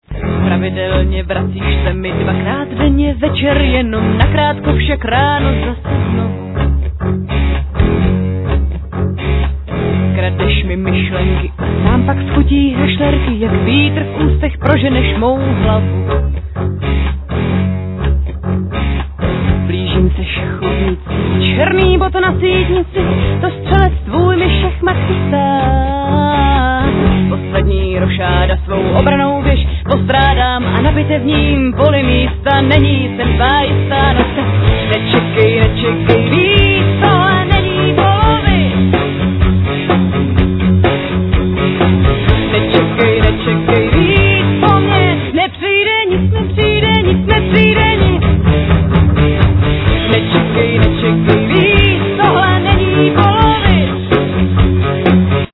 Vocals
Double bass
El.guitar
Drums
Ac.guitar
Accordion
Vocals, Flute
Violin, Viola
Saxophone